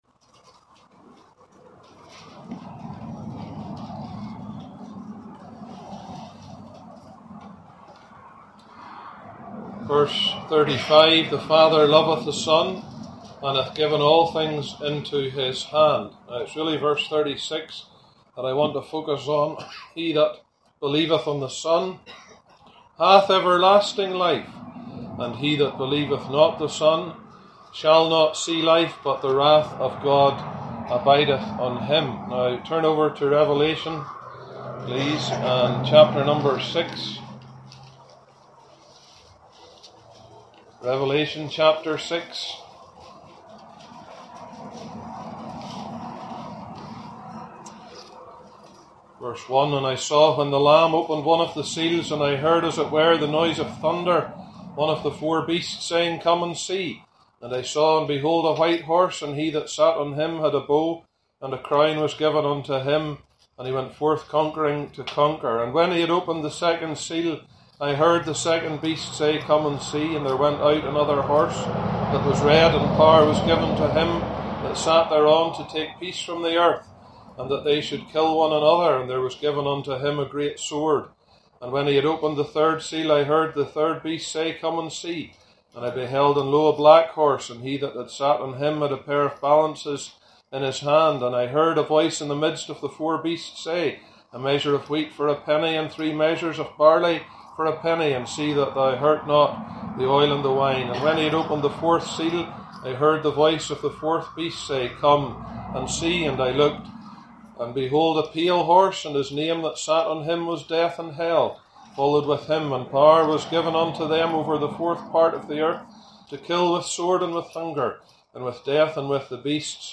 2025 Gospel Tent